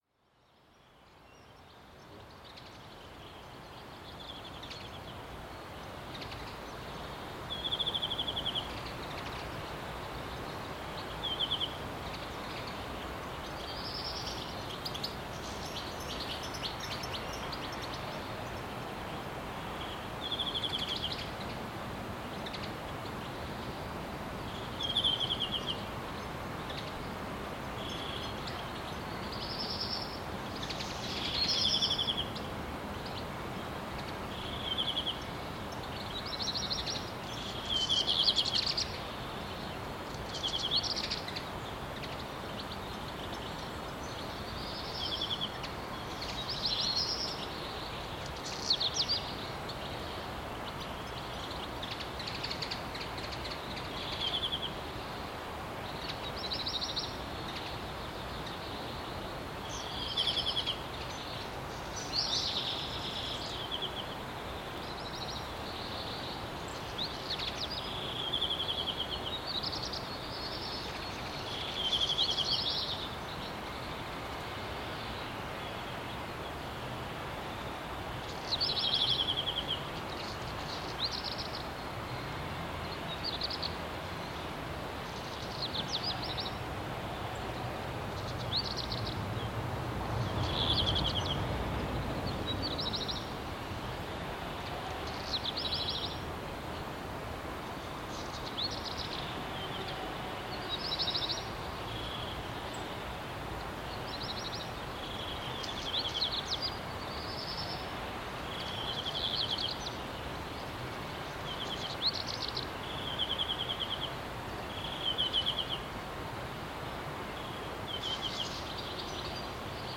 Zvočna krajina je v Tehniškem muzeju Slovenije v Bistri te dni popolnoma drugačna od običajne. Namesto vrveža obiskovalcev se oglaša narava. Sliši se samo petje številnih ptičev in šumenje reke Bistre.
Zvok-praznega-TMS.mp3